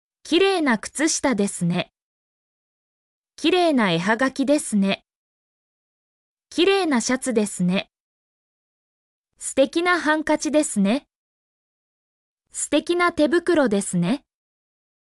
mp3-output-ttsfreedotcom-42_vxRMhYz2.mp3